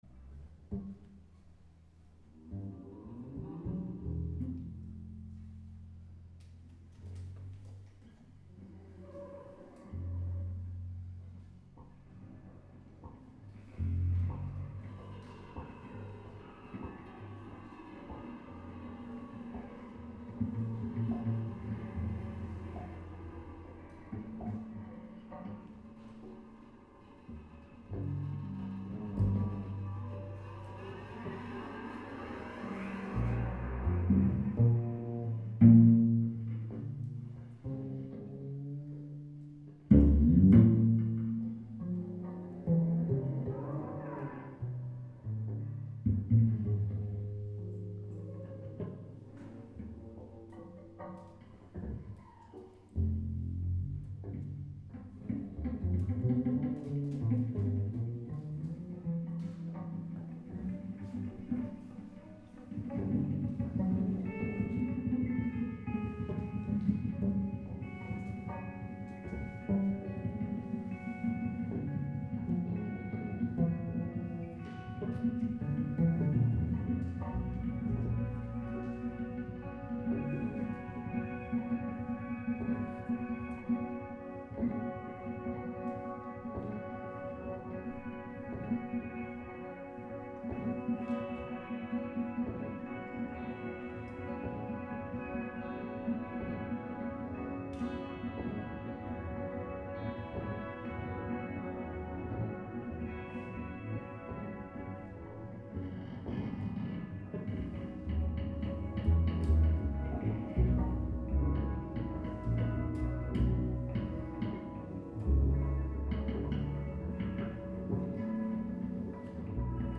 live from the Fridman Gallery Listening Room